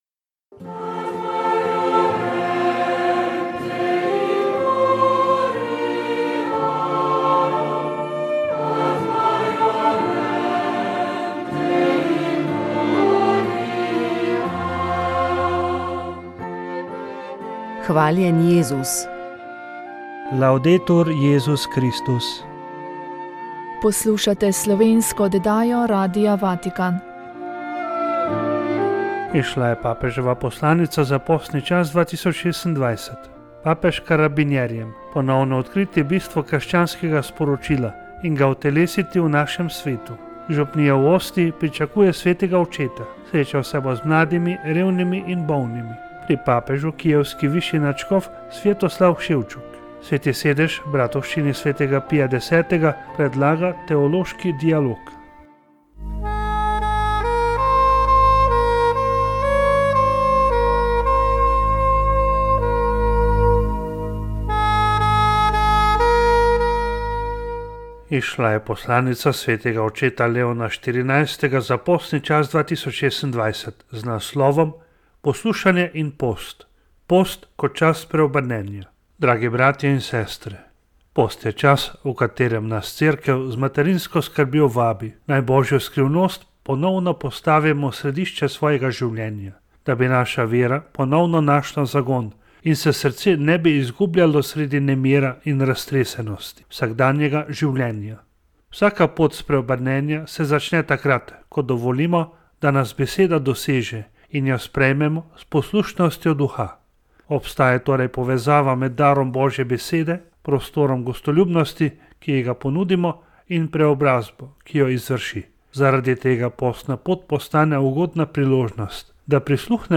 Pogovori z Vosovci